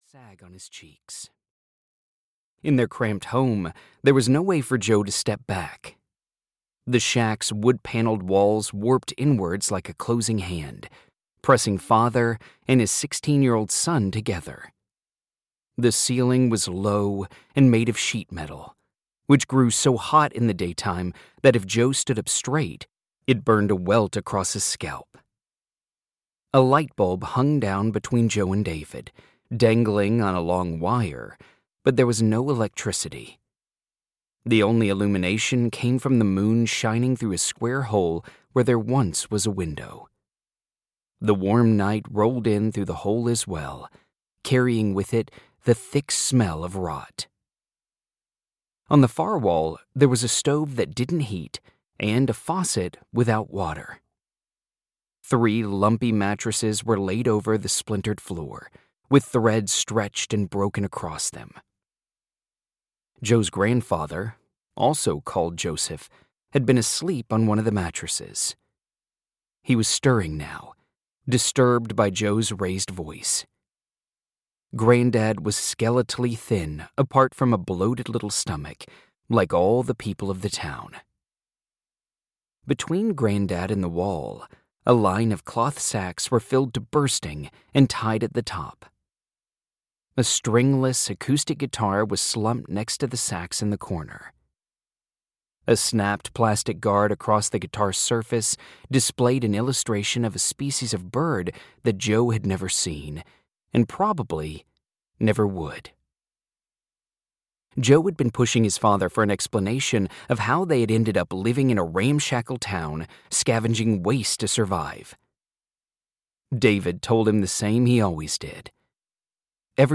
Audio knihaLandfill Mountains (EN)
Ukázka z knihy